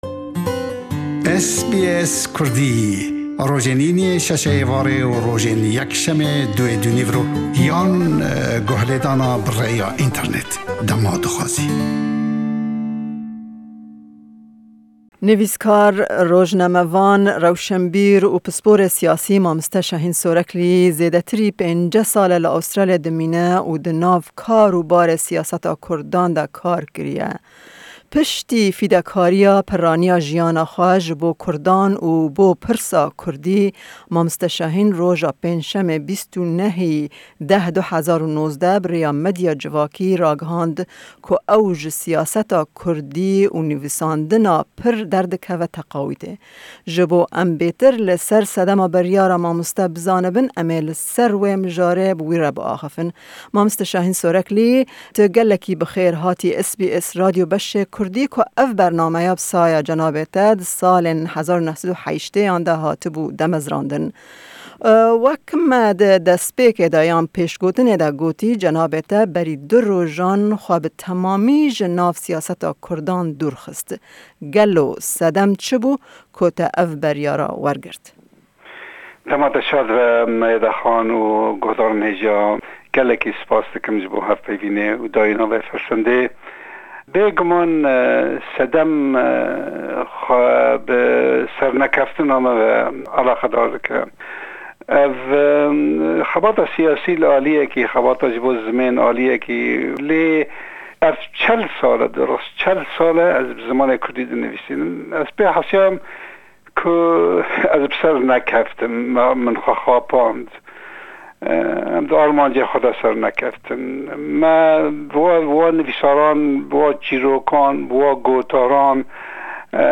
Em di vê hevpeyvînê de sedemên wê yekê ji mamoste dipirsin.